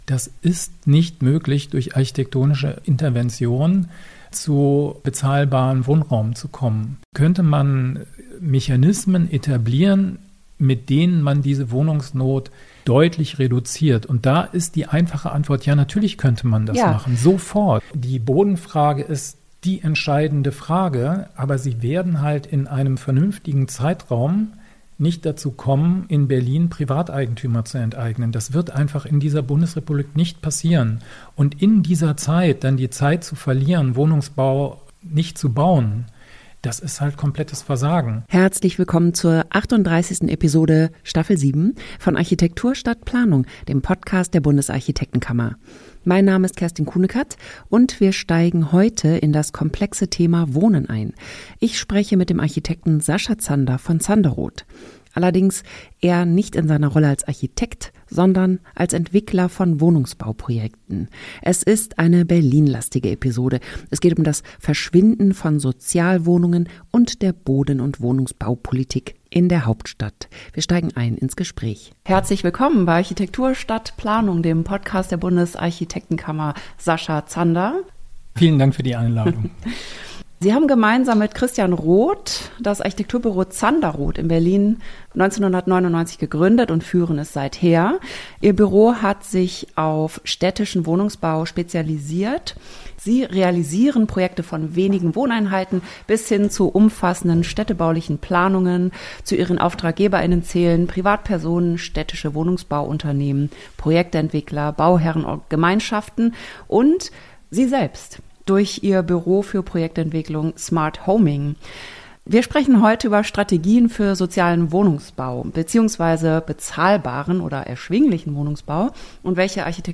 Interview-Podcast